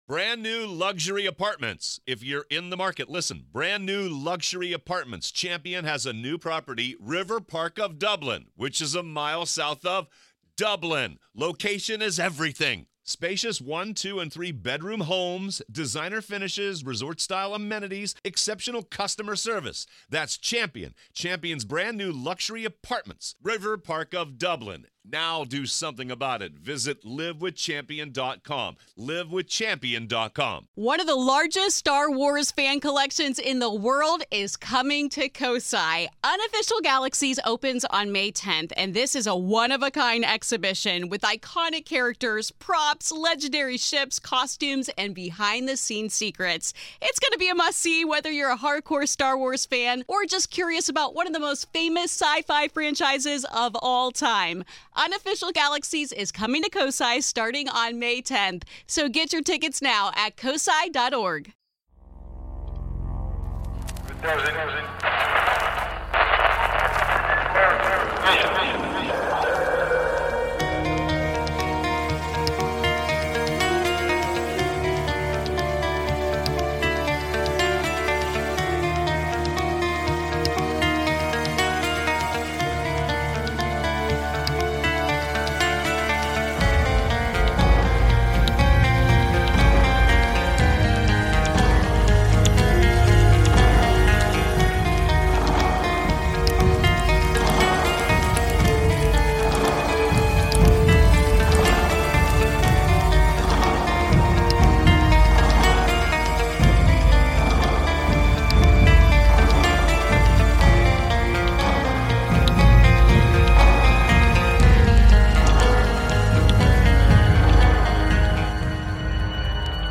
Tonight I play a few ghostly calls, a weird monster in Georgia, a scary dog and the infamous Ouija demon Zozo.